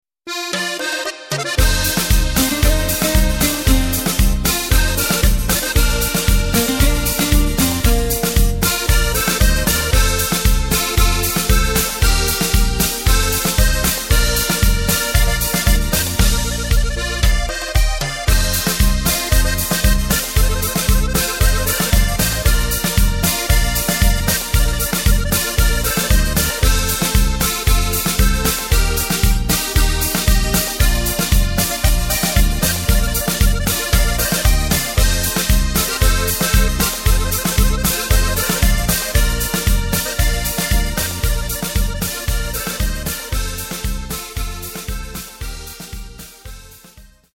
Tempo:         115.00
Tonart:            Bb
Coverversion (Schlager) aus dem Jahr 2021!
Playback mp3 mit Lyrics